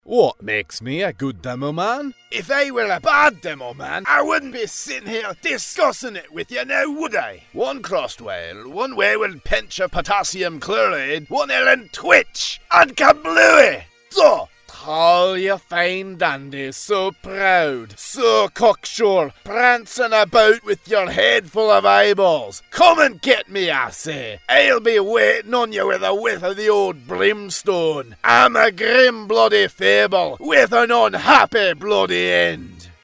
scottish | character
Scottish_Character_Demo.mp3